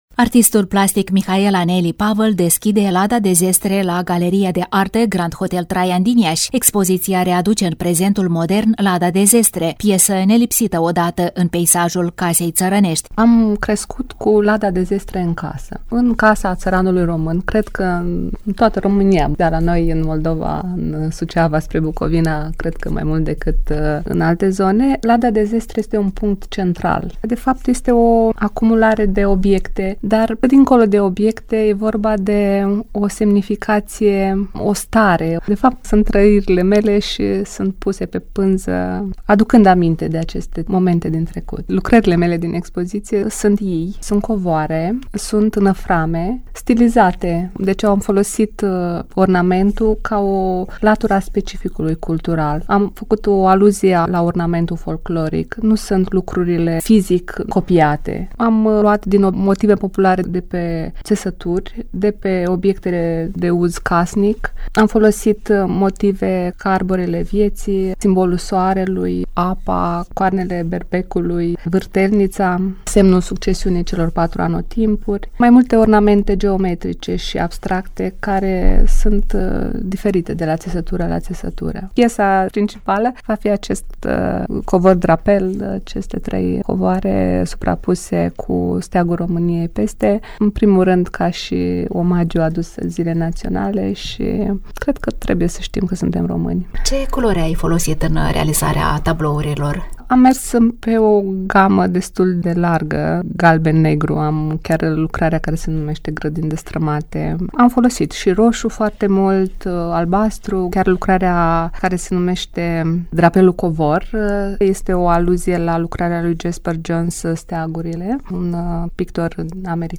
Reportaj